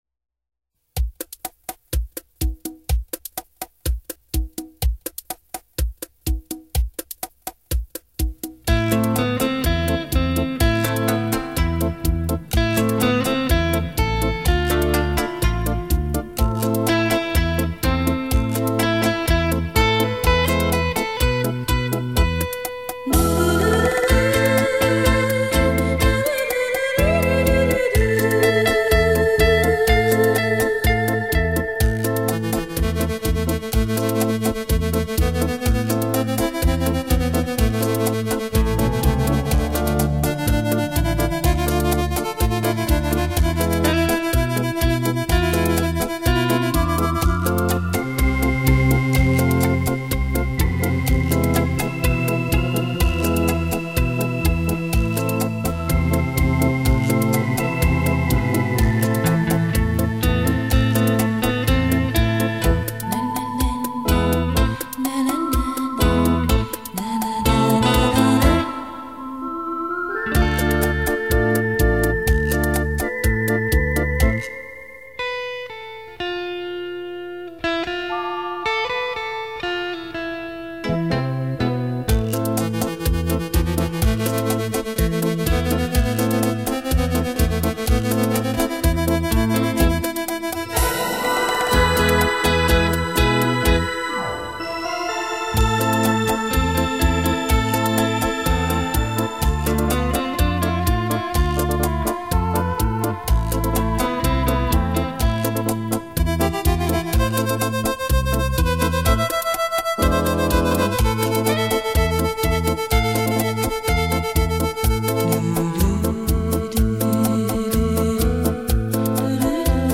本张专辑系列采用的是，结合了最新音频分离与合成技术和软件音源模拟
冲击力超强。